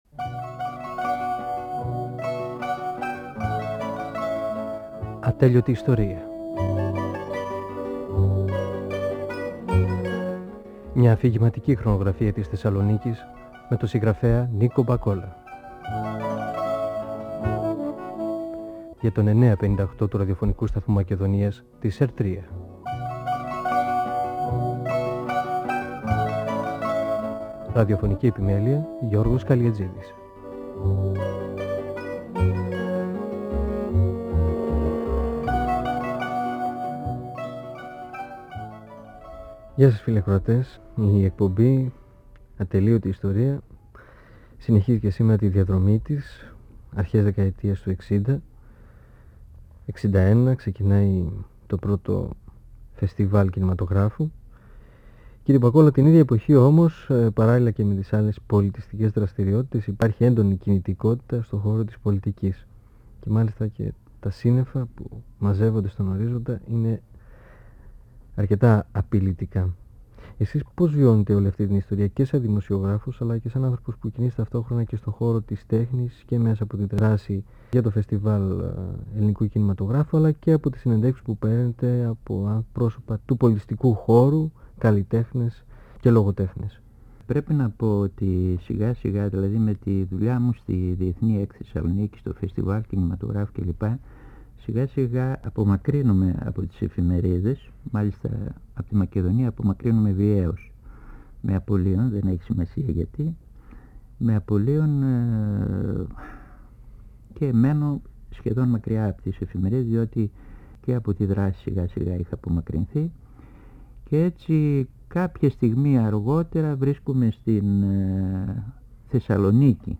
Ο πεζογράφος Νίκος Μπακόλας (1927-1999) μιλά για την απόλυσή του, αρχές δεκαετίας του 1960, από την εφημερίδα «Μακεδονία» και τη συνεργασία του με τη«Θεσσαλονίκη», πριν από τη δικτατορία των συνταγματαρχών.